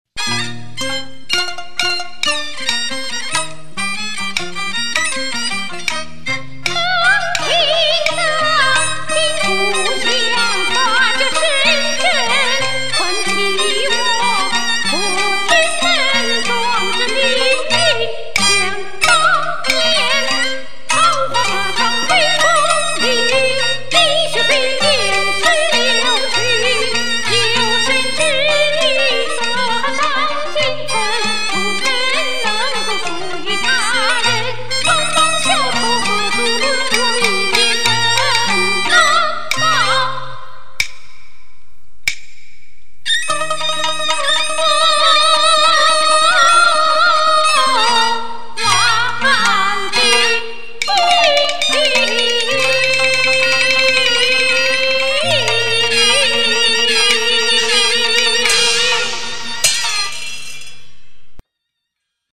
谢谢加州戏迷点俺唱戏，俺就狗胆反串一小段梅派青衣。
【西皮流水】